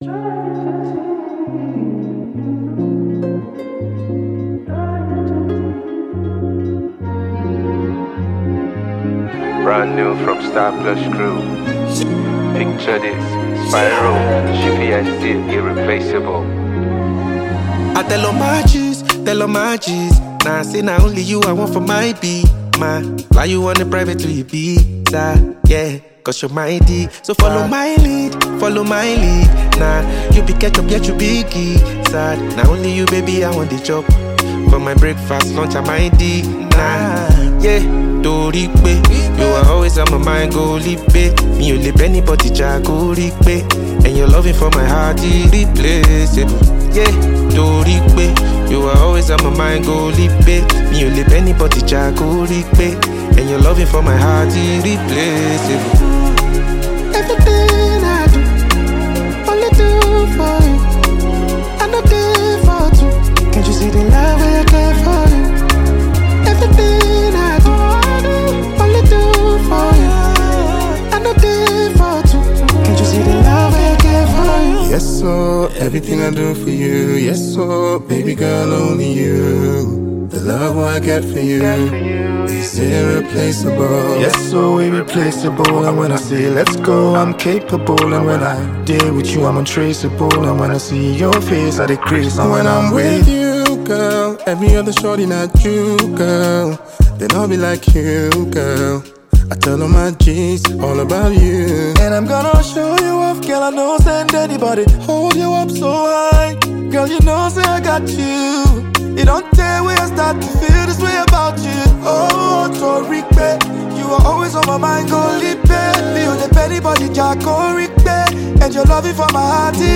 Afrobeats
Nigerian R&B group